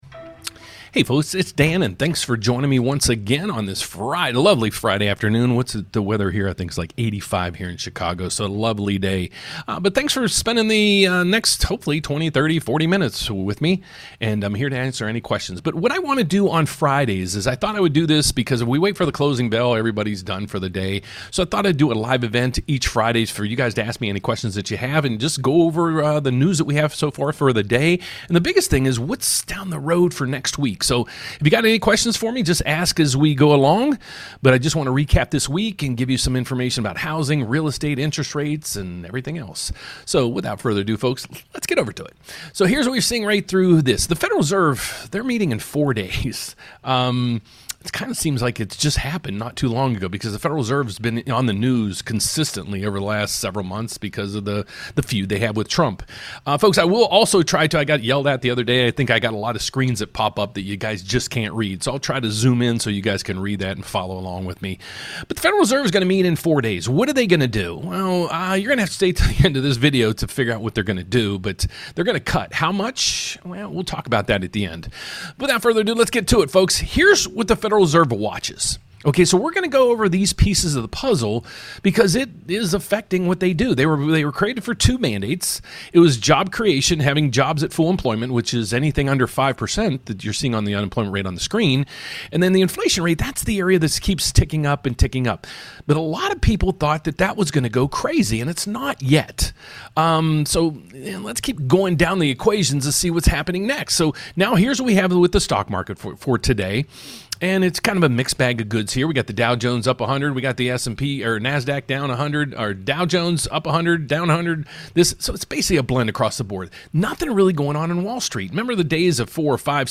📺 Friday Rate Update LIVE: Markets, Mortgage Rates & The Week Ahead